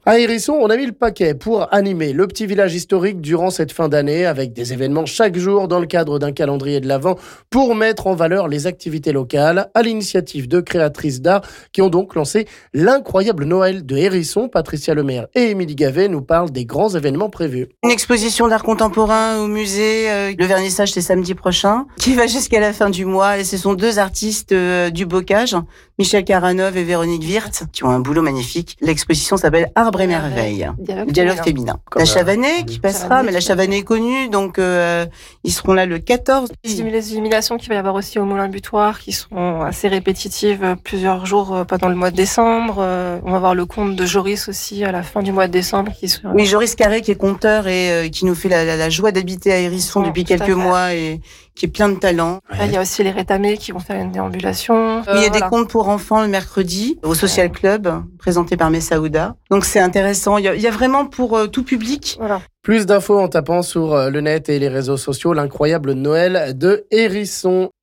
Les organisatrices nous détaillent quelques uns des événements prévus...